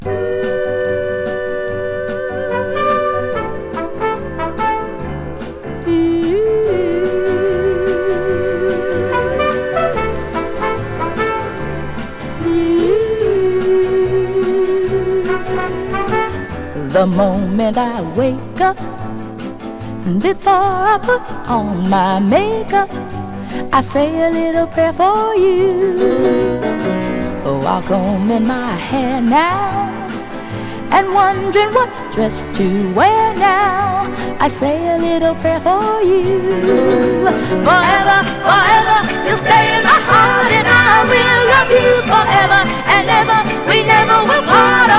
Pop, Soul, Vocal　USA　12inchレコード　33rpm　Stereo